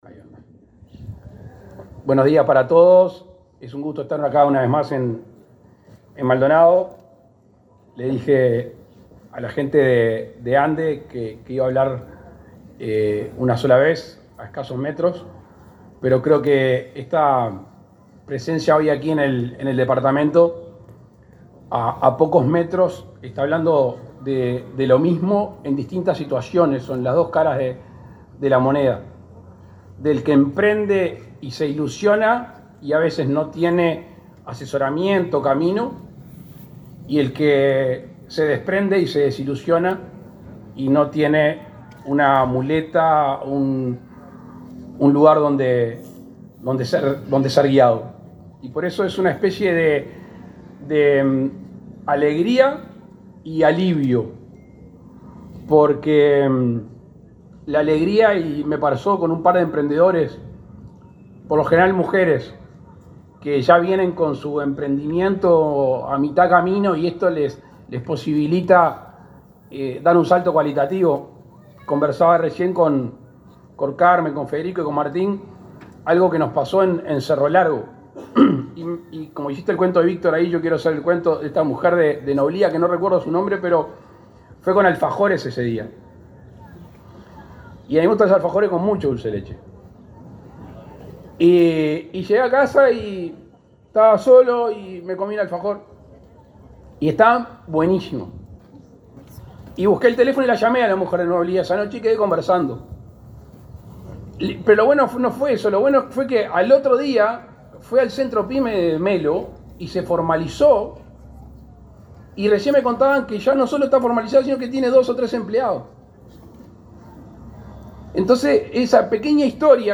Palabras del presidente Luis Lacalle Pou
Palabras del presidente Luis Lacalle Pou 30/09/2022 Compartir Facebook X Copiar enlace WhatsApp LinkedIn La Intendencia de Maldonado y la Administración de los Servicios de Salud del Estado (ASSE) implementan un plan piloto de prevención y tratamiento de adicciones e instalaron un centro a tales fines. El acto inaugural fue encabezado por el presidente de la República, Luis Lacalle Pou.